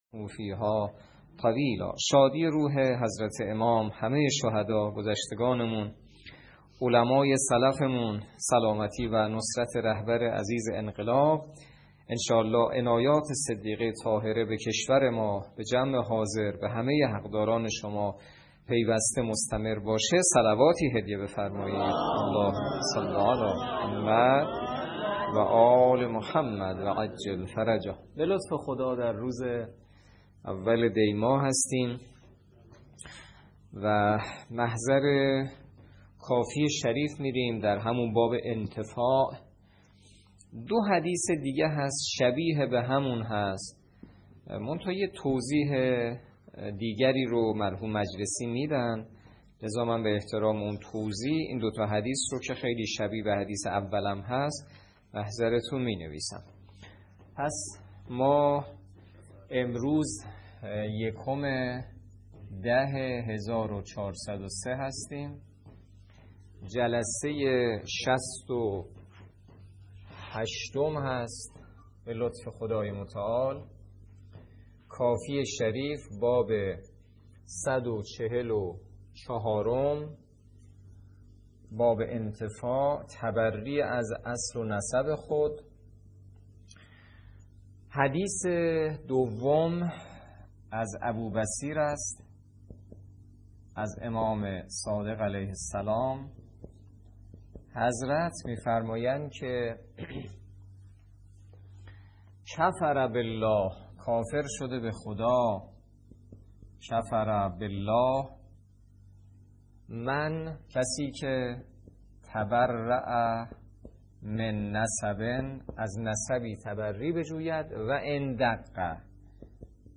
درس فقه الاجاره نماینده مقام معظم رهبری در منطقه و امام جمعه کاشان - سال سوم جلسه شصت و هشت